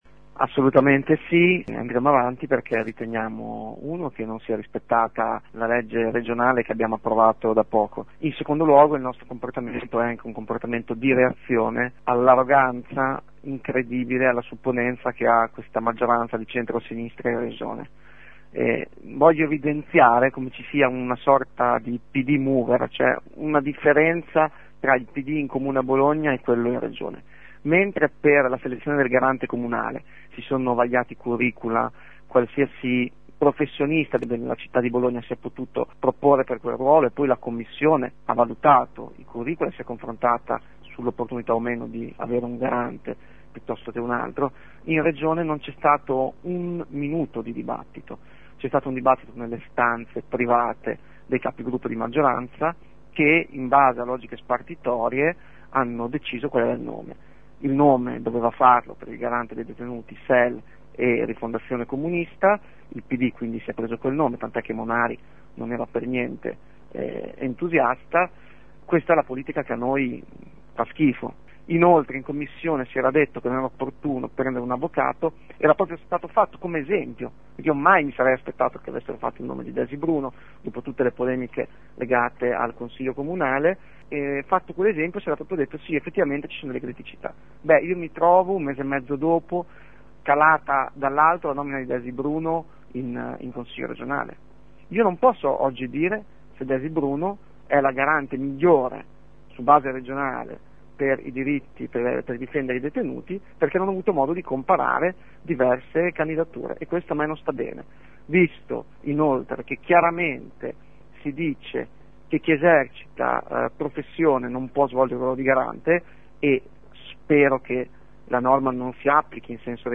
Il capogruppo dei grillini in Regione Giovanni Favia spiega ai nostri microfoni il motivo della contrarietà alla nomina dell’avv. Bruno.